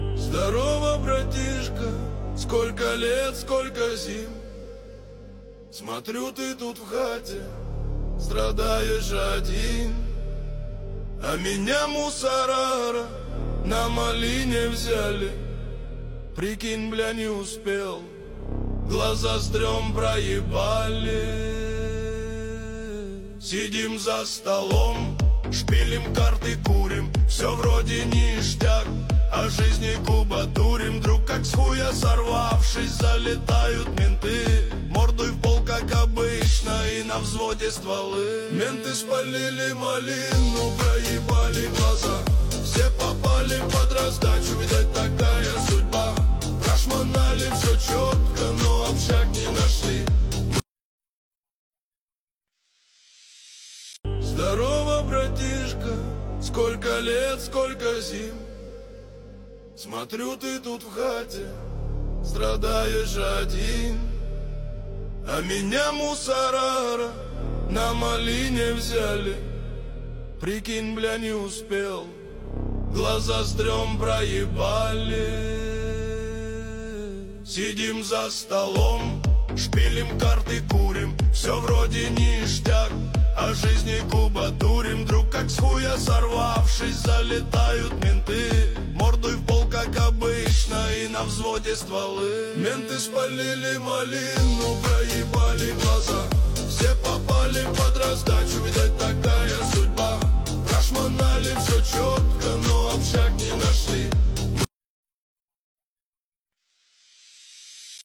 Pop, Русские поп песни